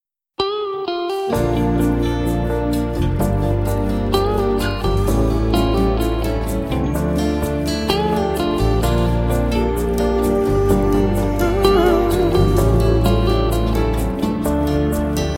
красивые
спокойные
без слов